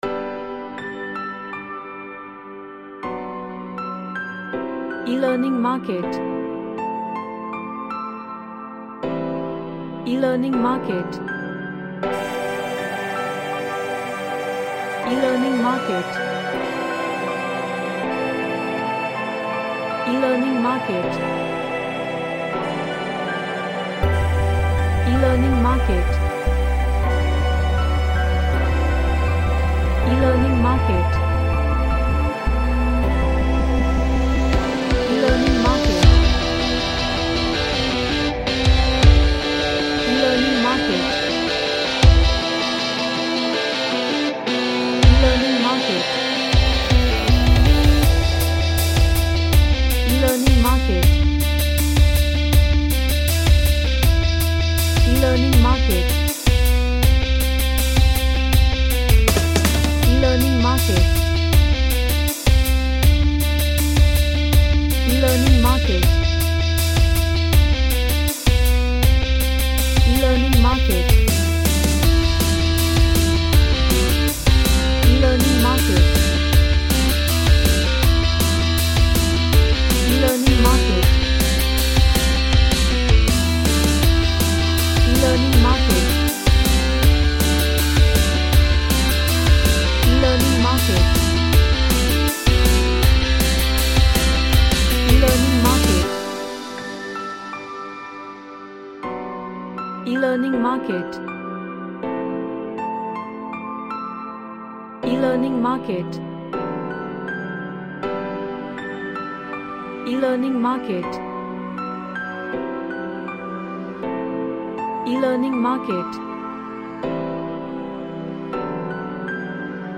Deep and hard metallic feel
Aggressive